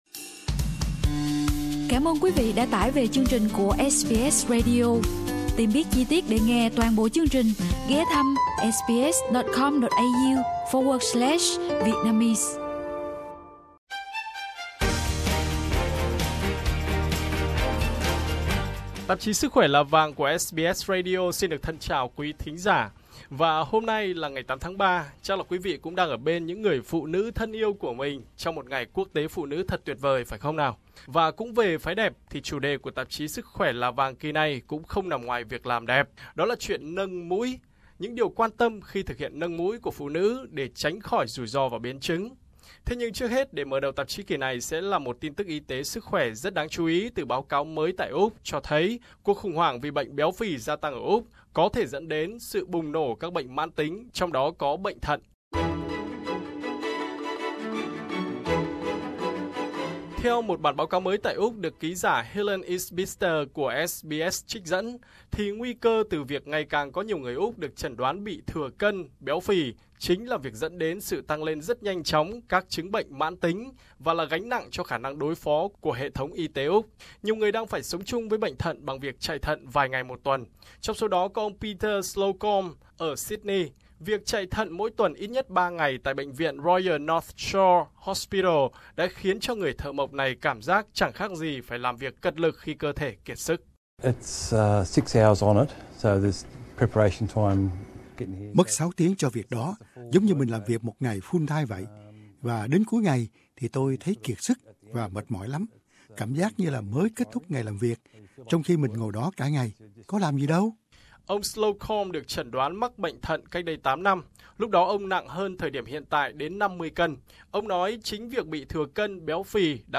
cuộc phỏng vấn